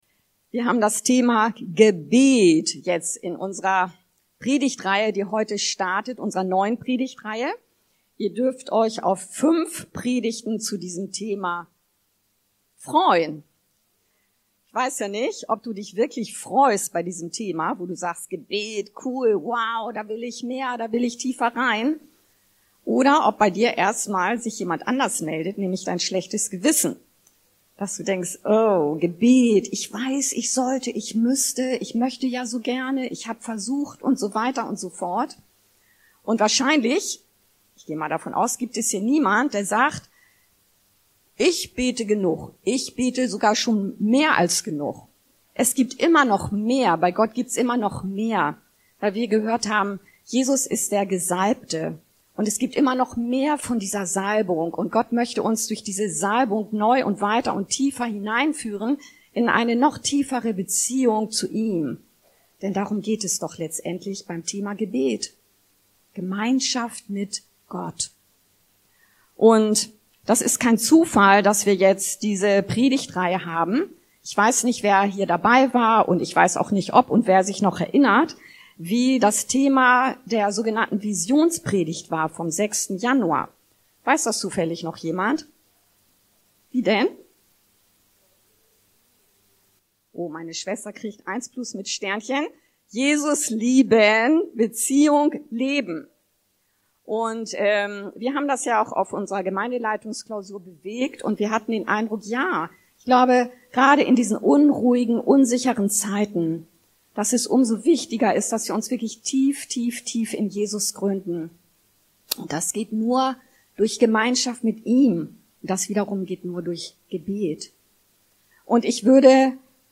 Predigtreihe GEBET.